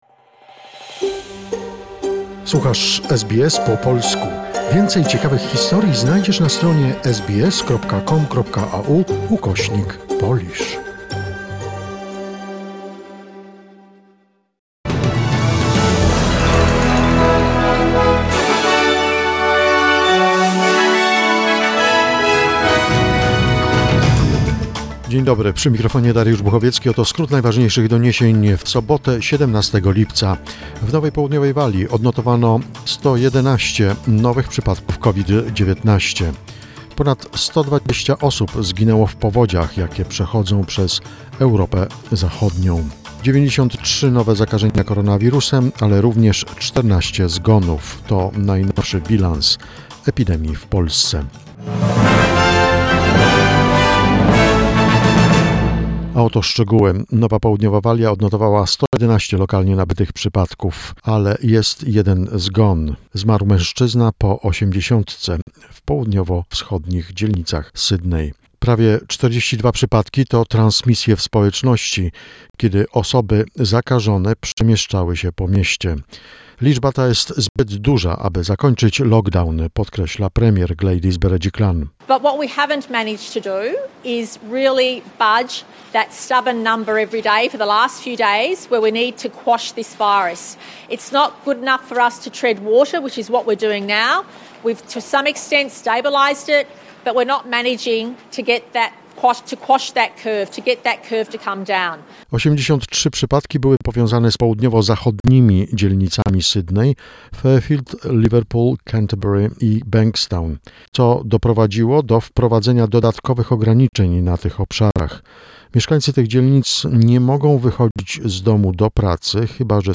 SBS News Flash in Polish, 17 July 2021